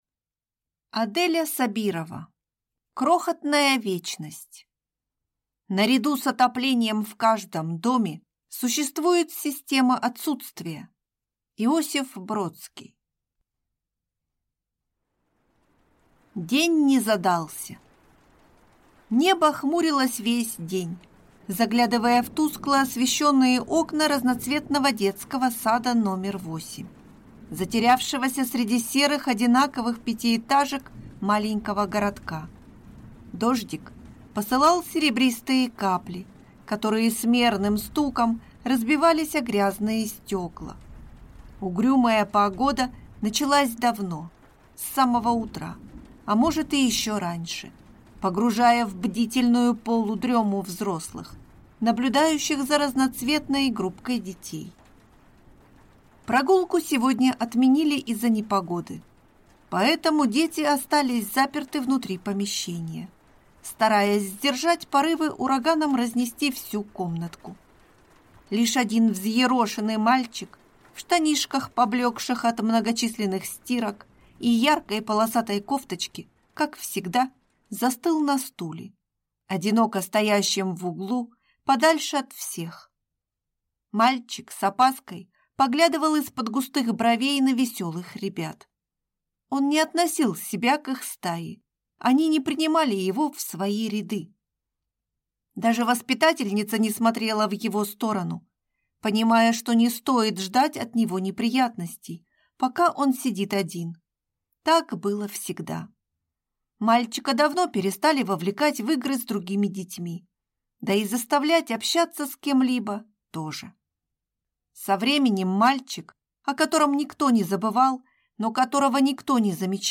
Аудиокнига Крохотная вечность | Библиотека аудиокниг